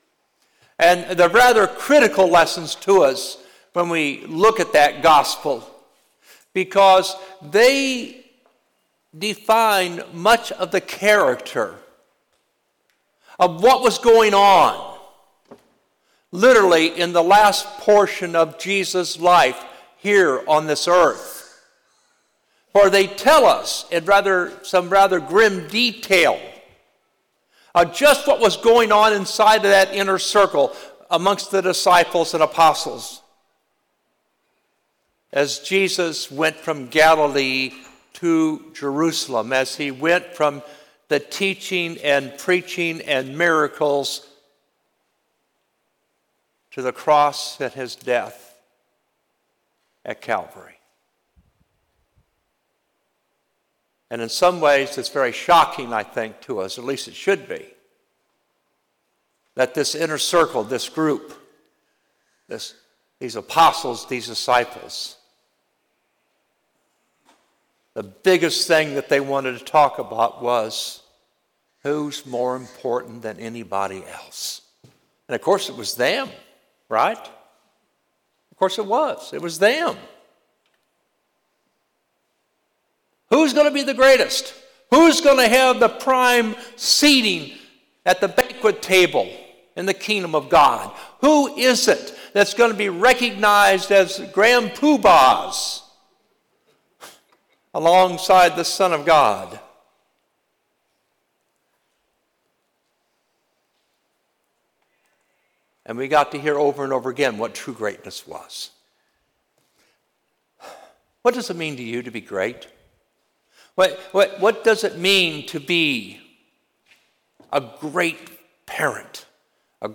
Sermon 10/06/24 Twentieth Sunday after Pentecost - Holy Innocents' Episcopal Church